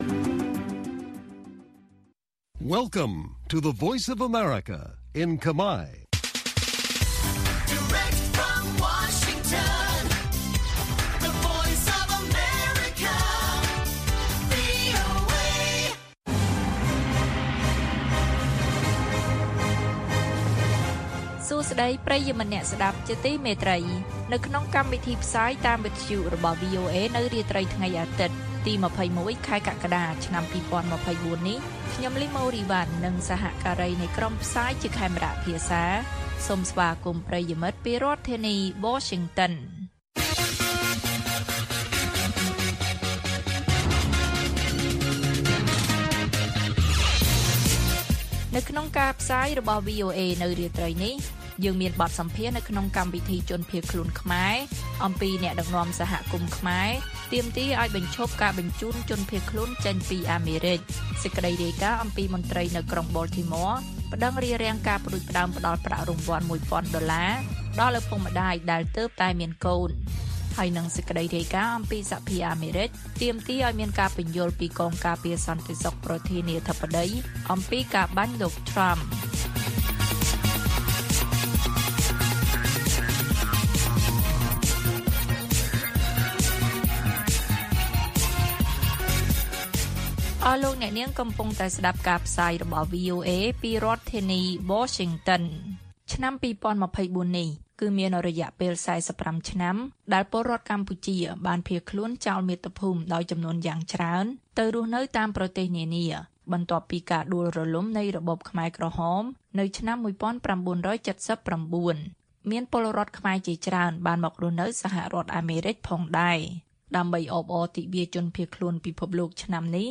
ព័ត៌មាននៅថ្ងៃនេះមានដូចជា បទសម្ភាសន៍នៅក្នុងកម្មវិធីជនភៀសខ្លួនខ្មែរអំពីអ្នកដឹកនាំសហគមន៍ខ្មែរទាមទារឱ្យបញ្ឈប់ការបញ្ជូនជនភៀសខ្លួនចេញពីអាមេរិក។ មន្ត្រីនៅក្រុង Baltimore ប្តឹងរារាំងការផ្តួចផ្តើមផ្តល់ប្រាក់រង្វាន់១.០០០ដុល្លារដល់ឪពុកម្តាយដែលទើបតែមានកូន និងព័ត៌មានផ្សេងទៀត៕